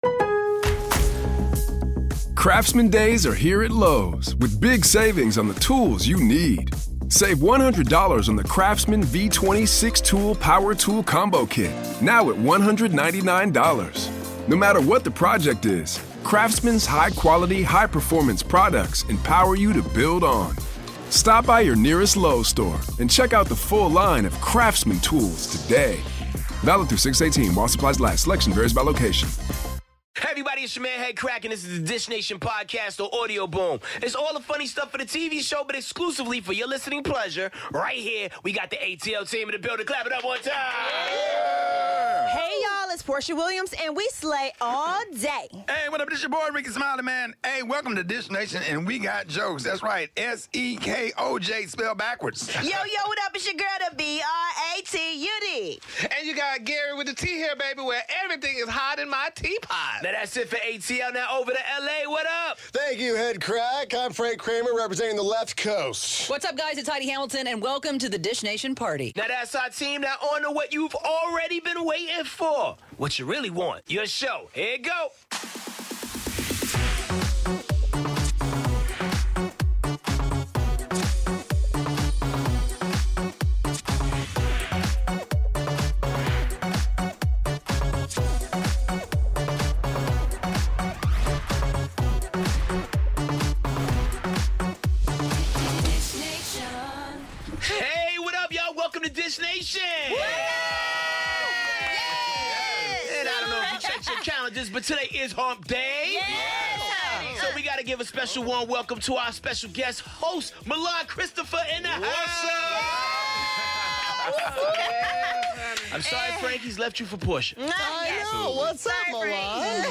Guest host: Milan Christopher. Jada Pinkett Smith keeps revealing too much in her new show and could a 19-year old be Jennifer Aniston's next boyfriend? Plus all the latest on Ayesha Curry, Kim Kardashian, Kyile Jenner, Andy Cohen and Porsha Williams' time on 'Love Connection.'